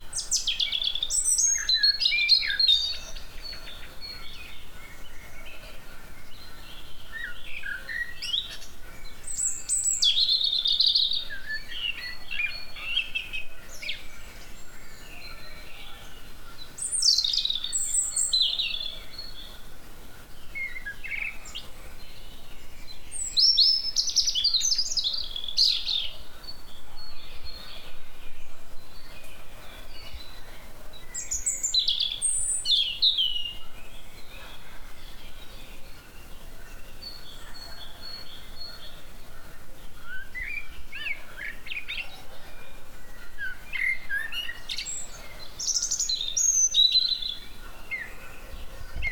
Audio file of bird song
birds-chirping-in-spring-season.mp3